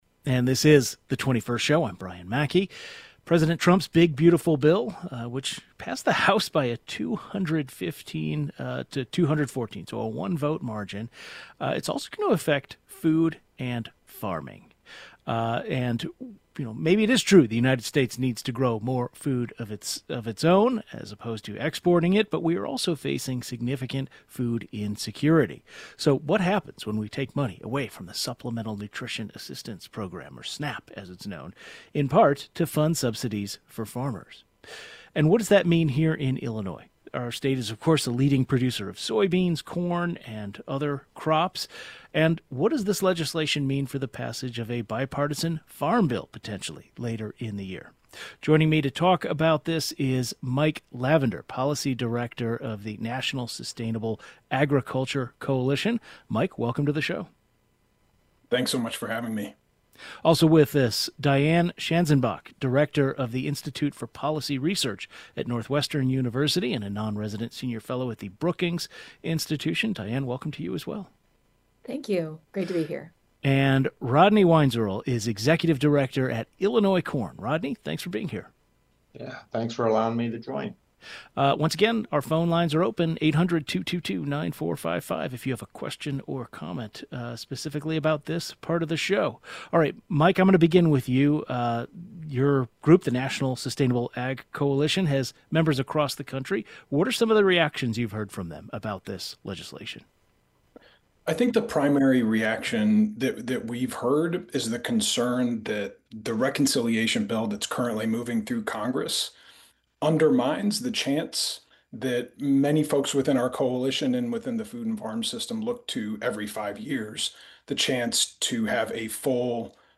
Two policy experts and the head of an Illinois-based organization representing corn farmers wiegh in.